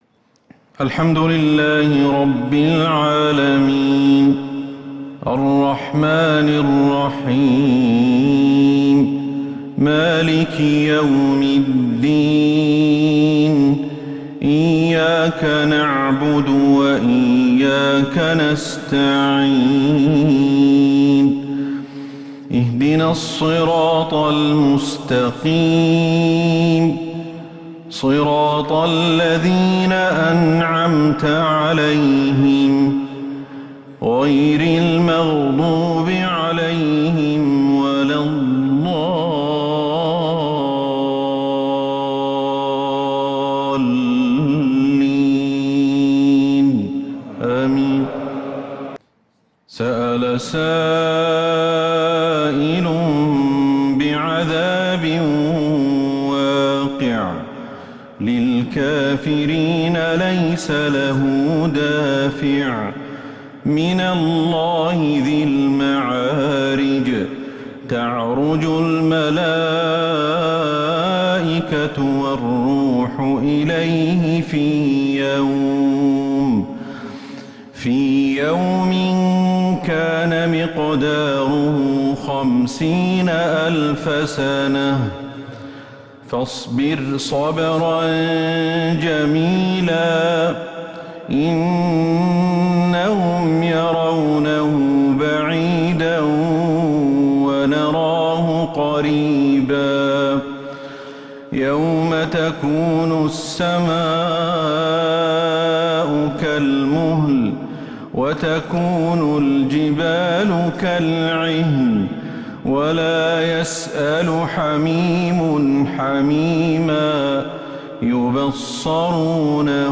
عشاء الأحد 20 ذو القعدة 1443هـ سورة {المعارج} > 1443 هـ > الفروض - تلاوات الشيخ أحمد الحذيفي